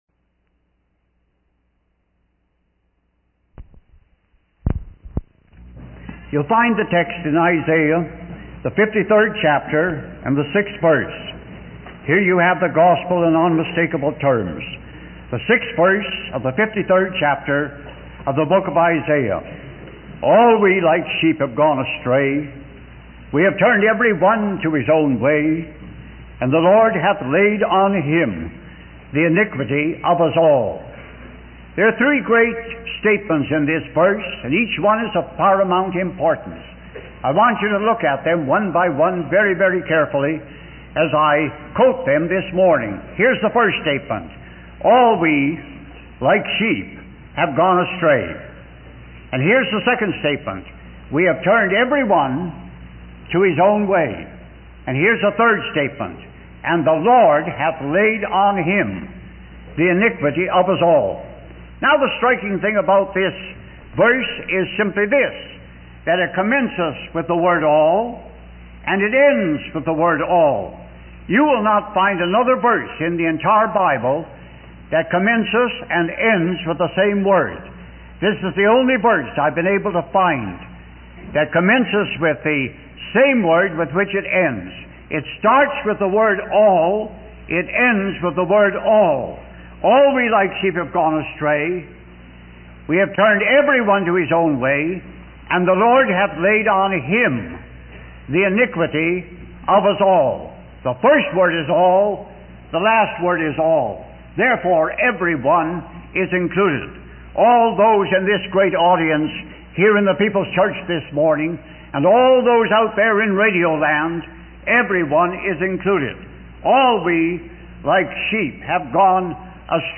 In this sermon, the preacher focuses on Isaiah 53:6, which states that all people have gone astray like sheep and turned to their own ways.